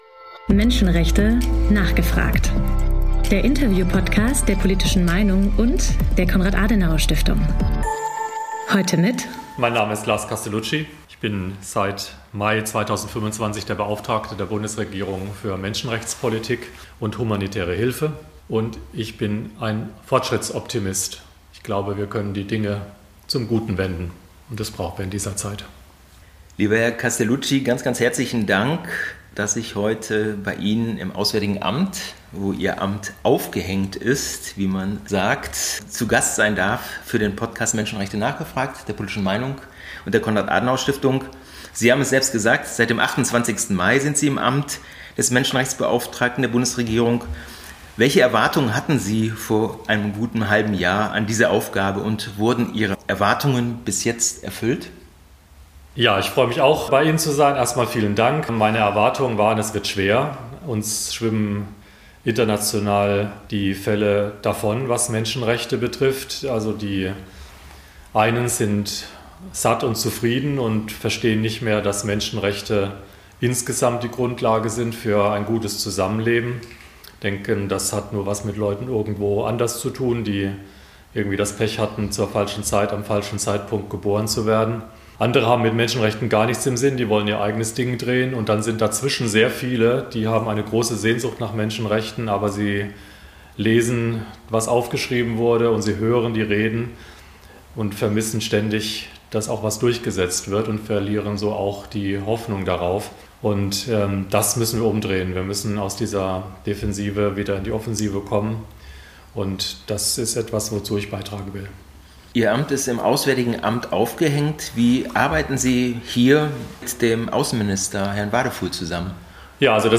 Beschreibung vor 6 Tagen Prof Dr. Lars Castelucci ist in der Bundesregierung der Beauftragte für Menschenrechtspolitik und humanitäre Hilfe.
Im Interview geht es vor allem um den Schutz der Menschenrechte als Teil der Außenpolitik. Wir sprechen über den Iran, über die Lage im Land und etwaige neue Sanktionen, über die humanitäre Katastrophe im Sudan und die Flüchtlingskriese.